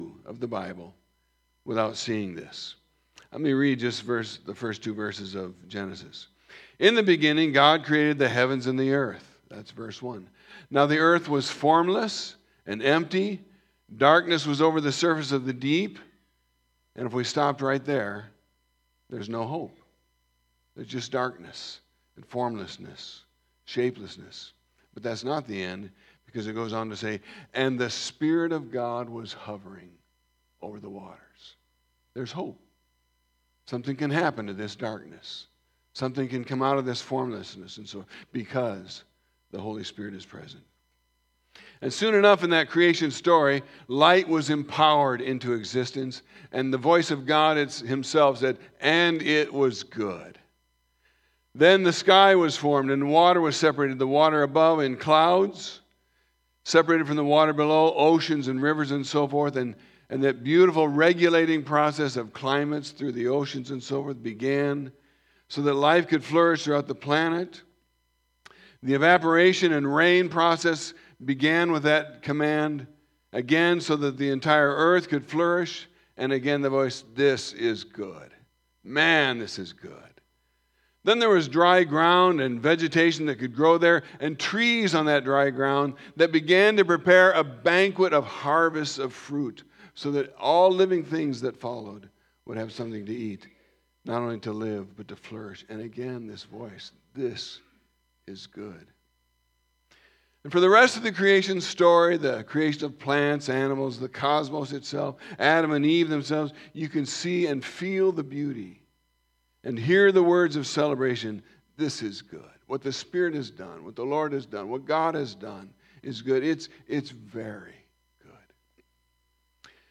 Hear recorded versions of our Sunday sermons at your leisure, in the comfort of your own personal space.
Service Type: Sunday Morning